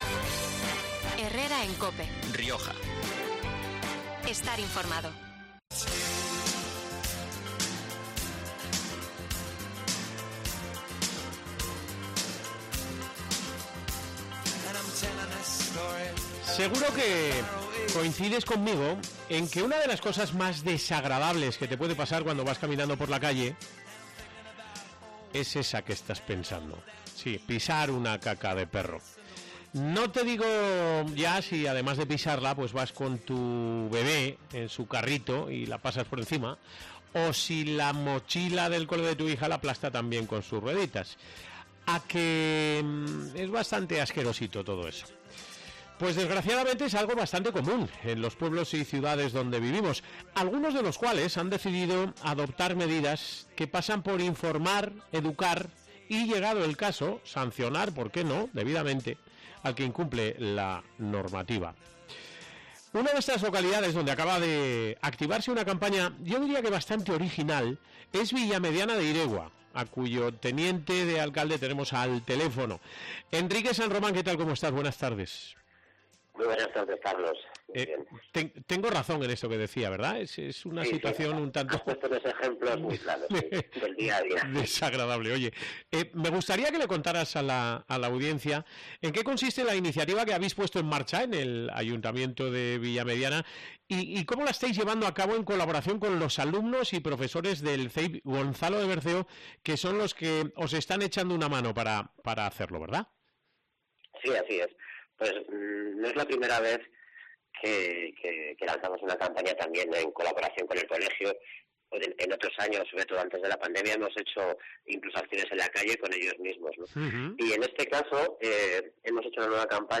Enrique San Román, Teniente Alcalde, ha explicado en qué consiste la campaña y cómo la va a llevar a efecto.